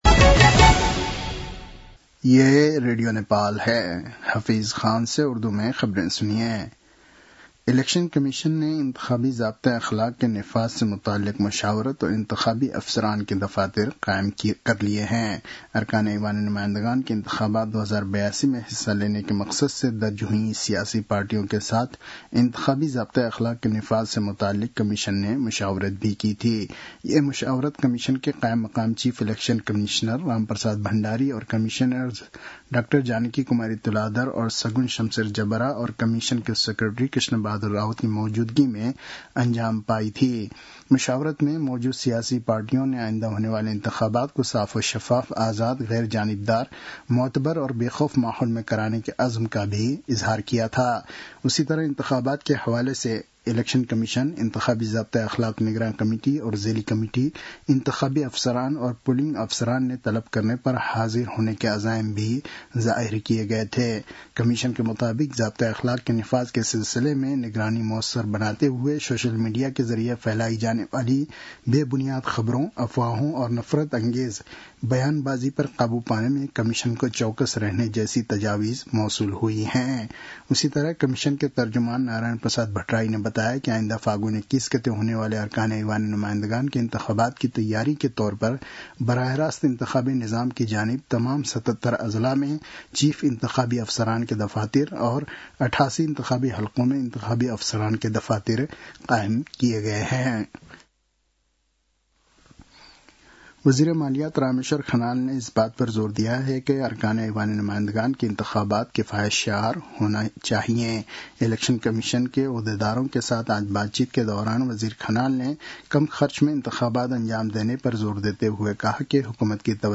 उर्दु भाषामा समाचार : ४ माघ , २०८२